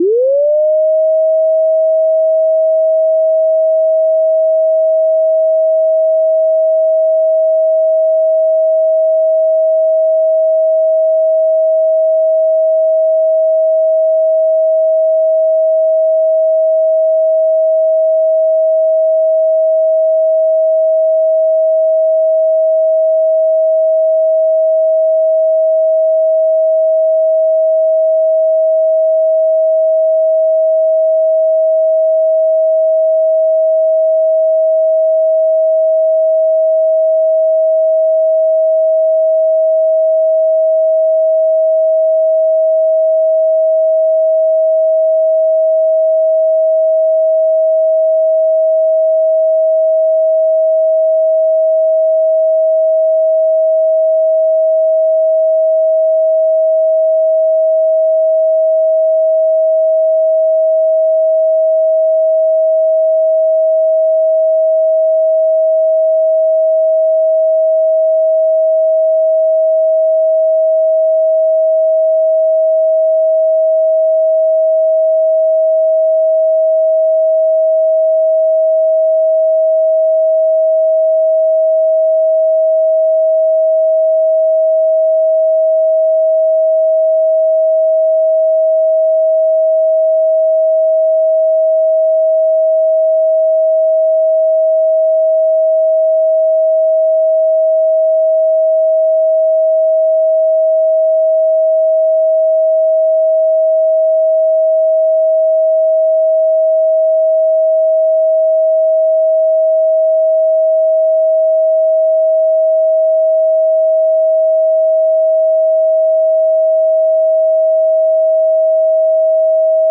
639 Hz Tone Sound Solfeggio Frequency
Solfeggio Frequencies